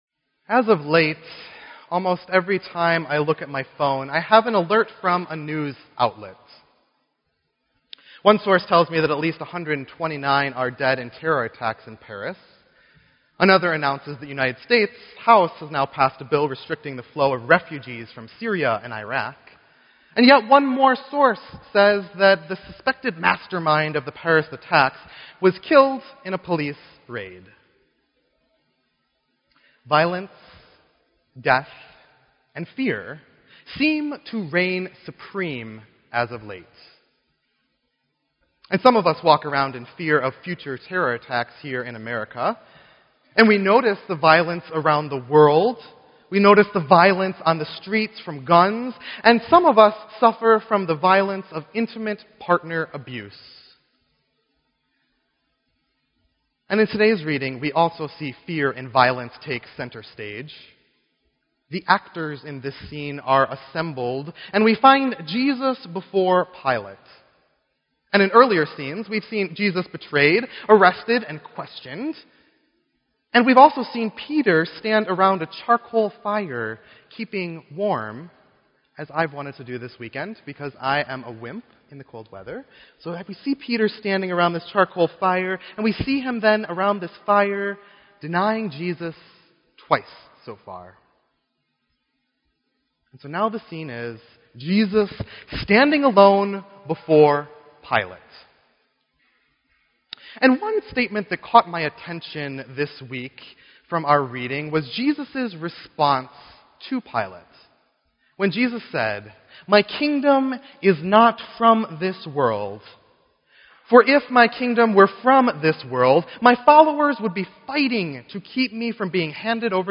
Wicker Park Lutheran Church Preaching Fall 2015